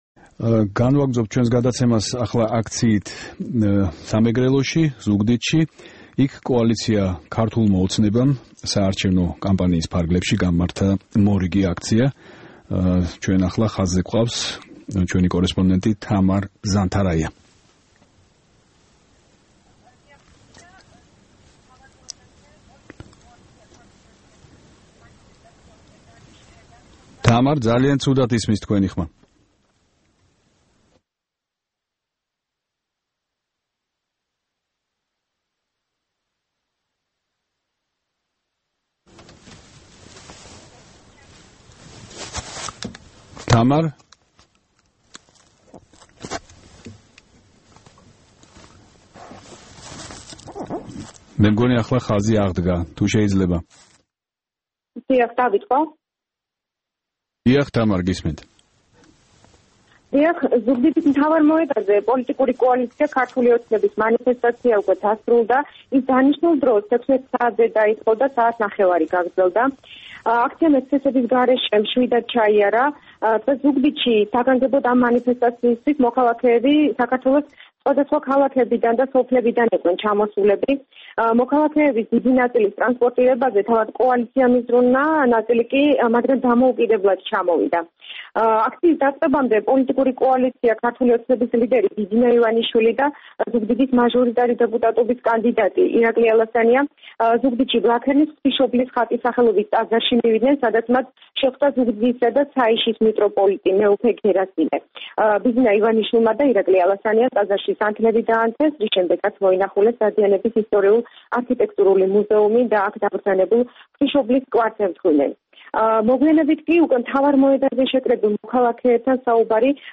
რეპორტაჟი ზუგდიდიდან